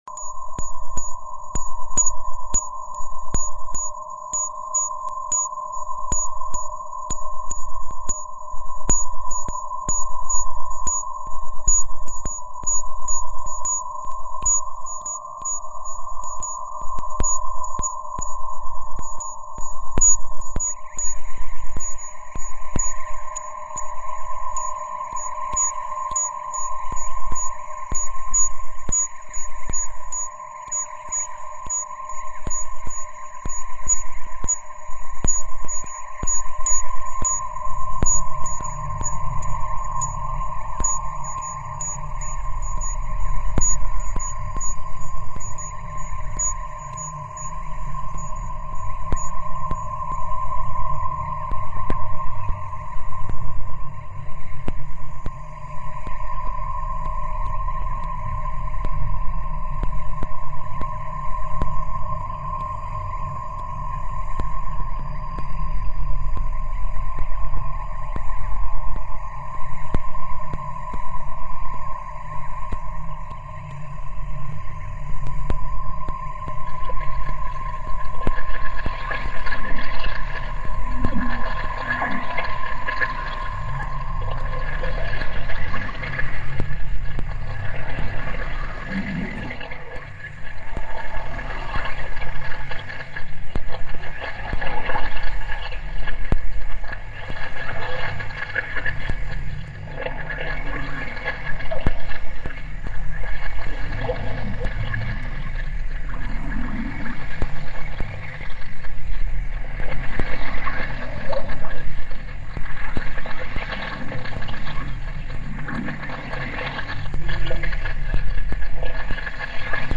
Experimental sound ,video and installation artist in Taiwan.